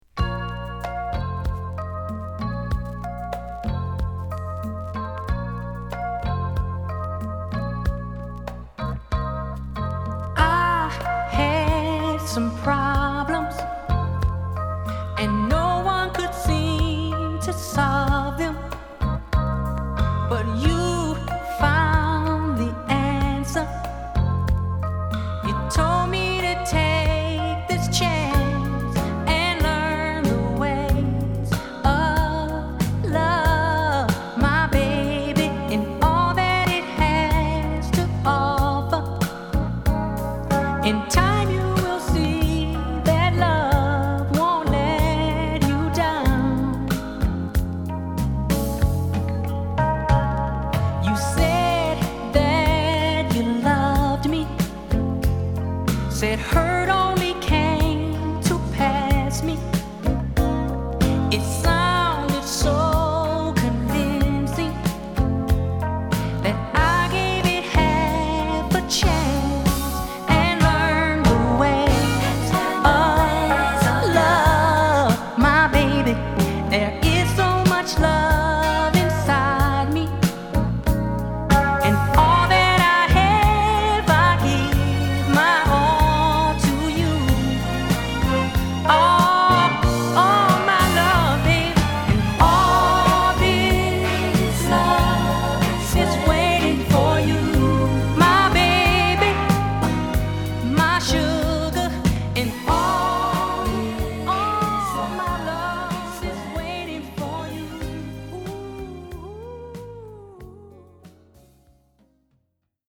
しっとりしたメロディと素晴らしいヴォーカルが絡む、文句無しの80'sメロウ・クラシック！